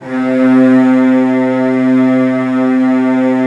CELLOS DN3-R.wav